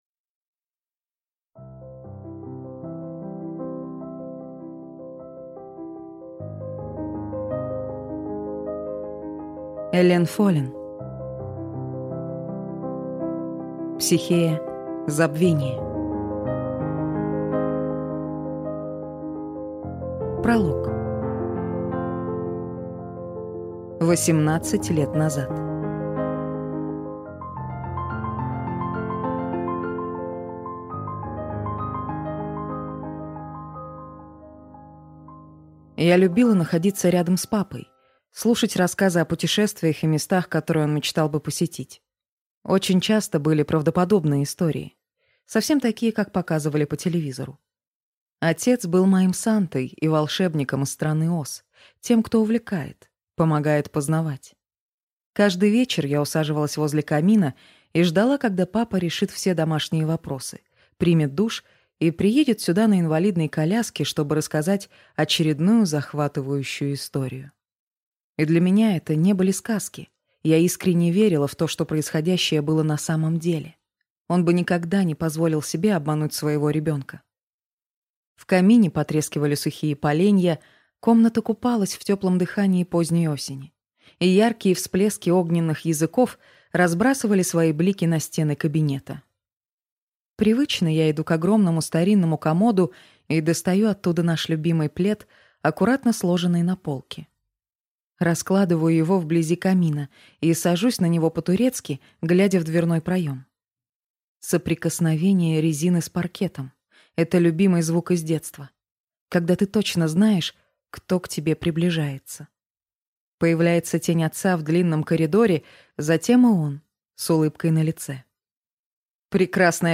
Аудиокнига Психея. Забвение | Библиотека аудиокниг